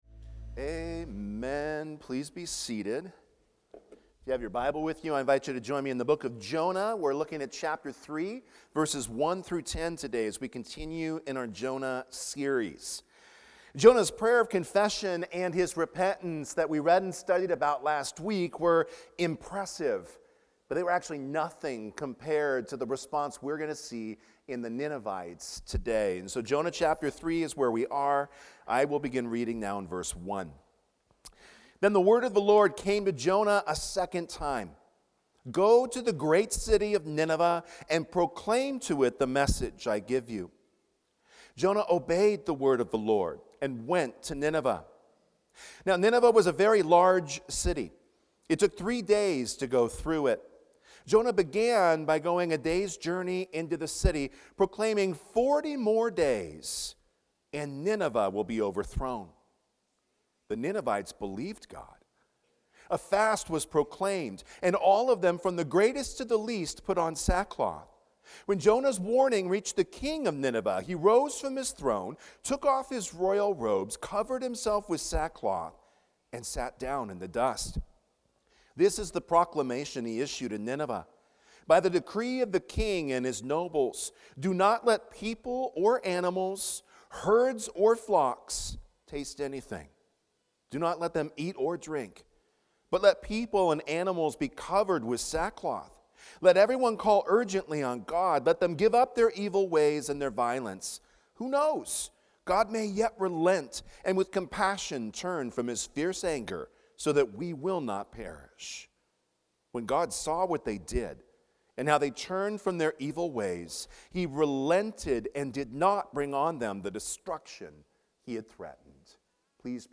Jonah's Obedience | Fletcher Hills Presbyterian Church